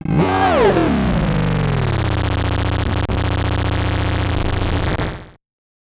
Glitch FX 45.wav